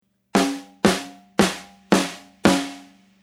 Here’s a second example featuring a snare drum.
Now, here’s the same track with distortion added as above:
Much snappier!
tipDistortion-SnareDirty.mp3